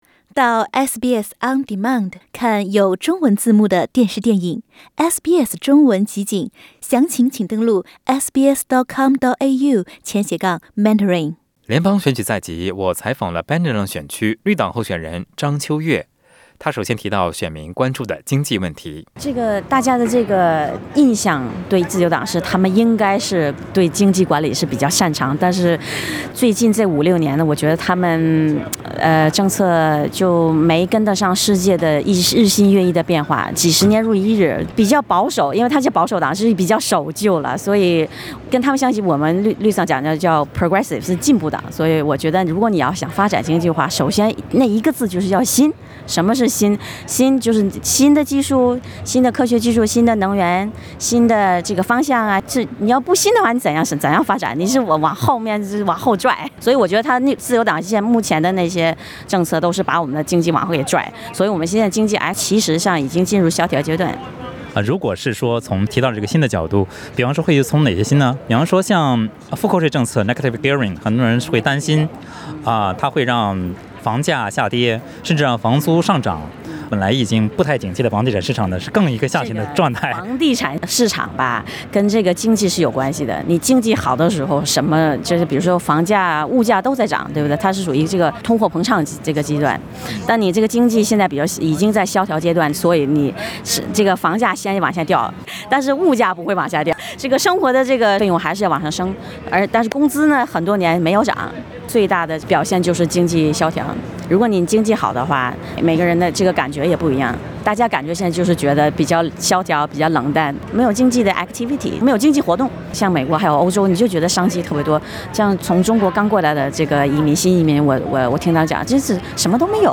绿党也计划推动电动汽车革命，绿党计划在2030年让澳大利亚100%普及可再生能源，这是趋势，并且有利澳洲经济。 请您点击收听详细的采访内容。